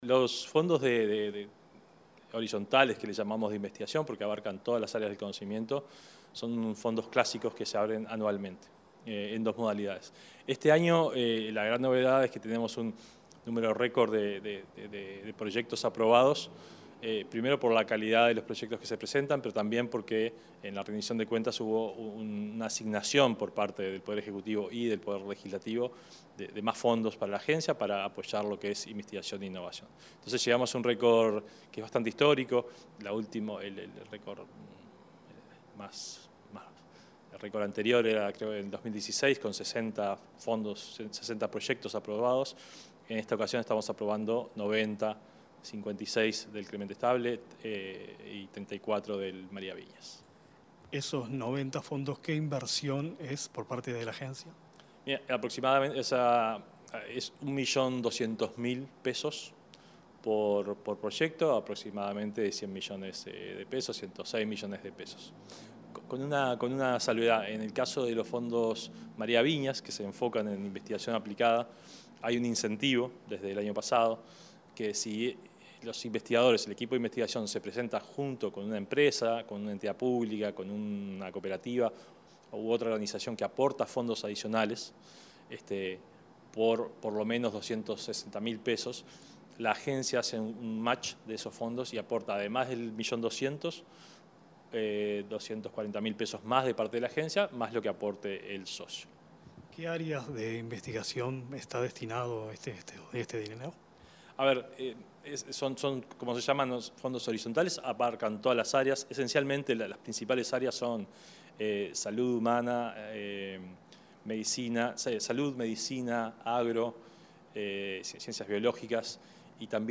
Entrevista al presidente de ANII, Flavio Caiafa
El presidente de esa dependencia, Flavio Caiafa, dialogó al respecto con Comunicación Presidencial.